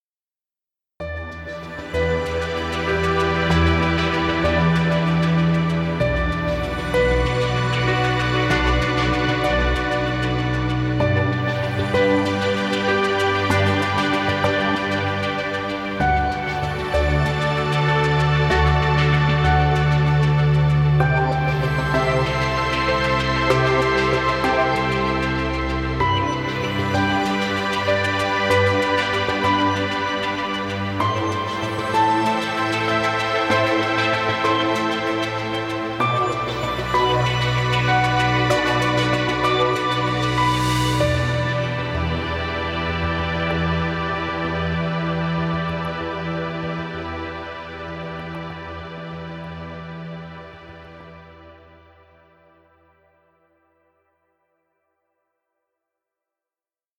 Ambient music.